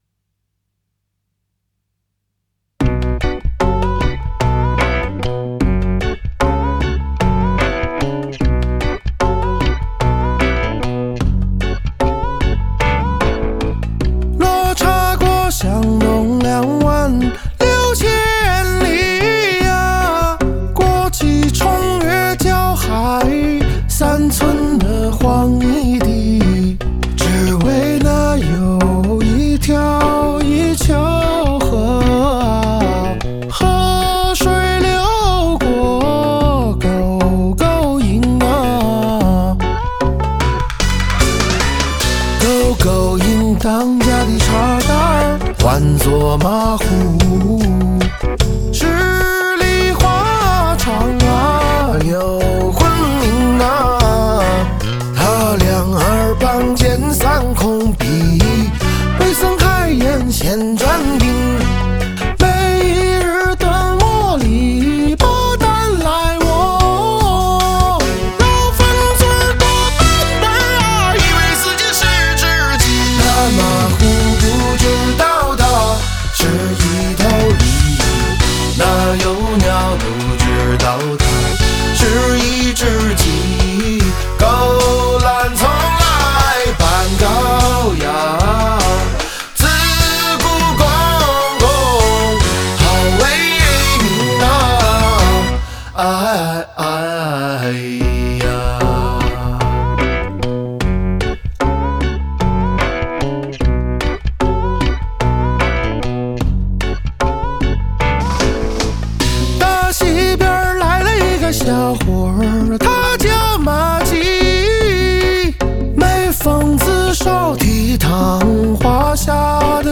此系列尝试构建流行音乐与民间传统文化共生共存的音乐生态